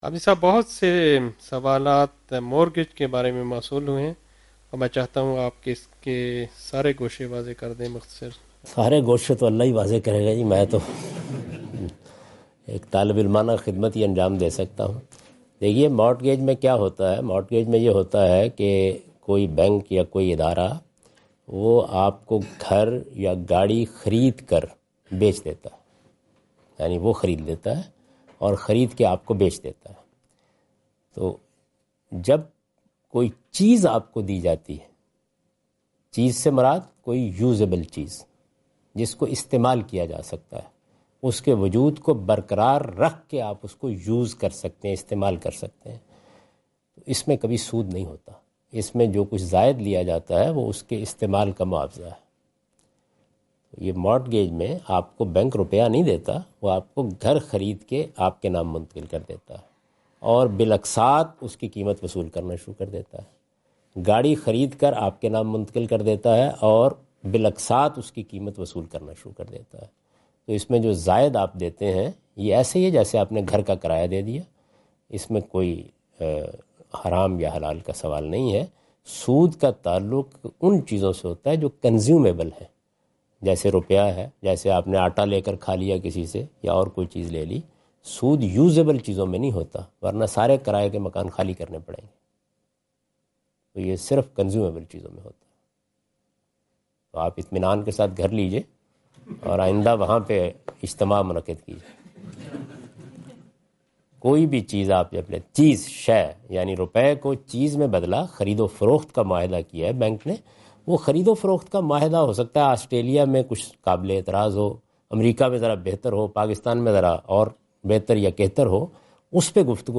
Javed Ahmad Ghamidi answer the question about "mortgage and interest" during his Australia visit on 11th October 2015.
جاوید احمد غامدی اپنے دورہ آسٹریلیا کے دوران ایڈیلیڈ میں "قسطوں پر لین دین اور سود" سے متعلق ایک سوال کا جواب دے رہے ہیں۔